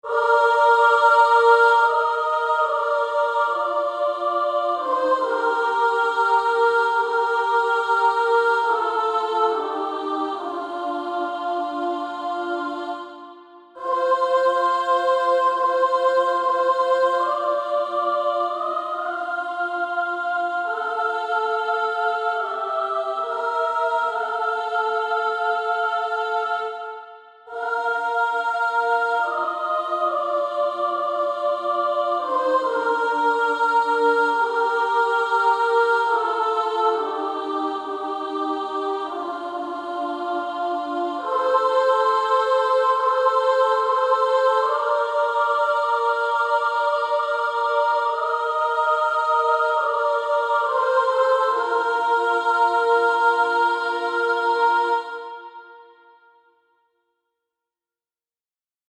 Practice Music tracks
Practice then with the Chord quietly in the background.